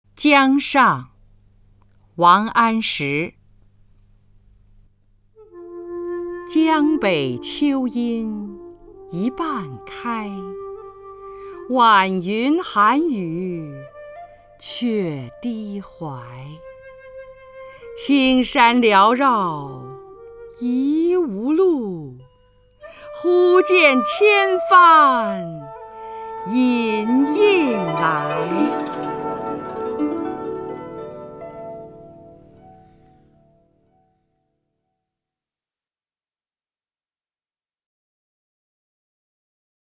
张筠英朗诵：《江上》(（北宋）王安石) (右击另存下载) 江上漾西风， 江花脱晚红。
名家朗诵欣赏